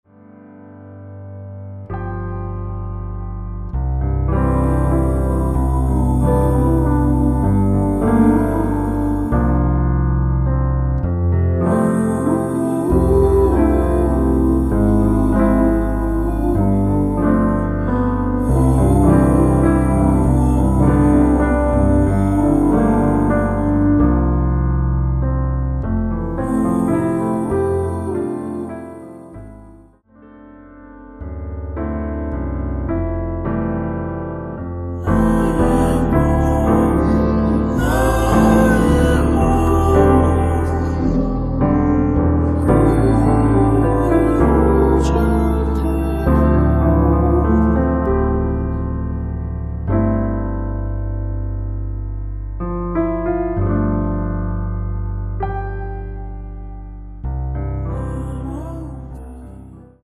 원키에서(-2)내린 코러스 포함된 MR입니다.(미리듣기 확인)
앞부분30초, 뒷부분30초씩 편집해서 올려 드리고 있습니다.
중간에 음이 끈어지고 다시 나오는 이유는